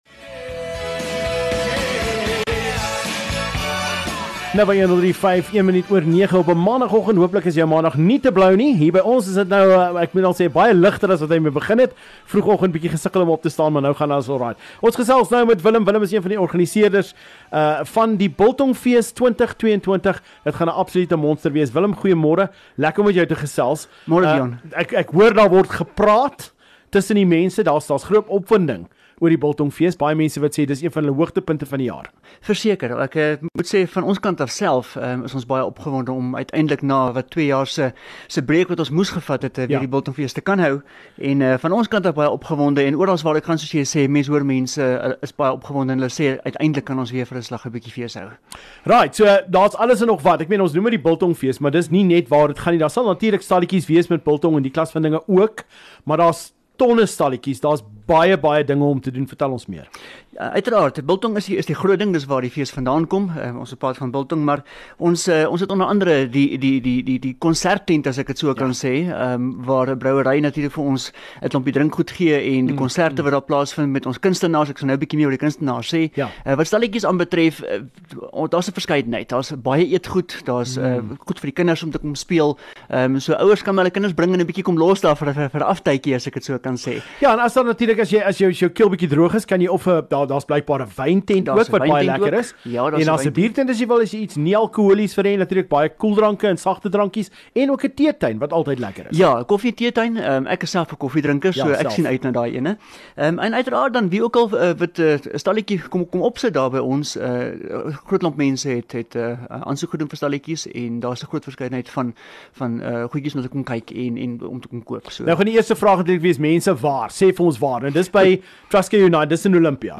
kuier in die ateljee...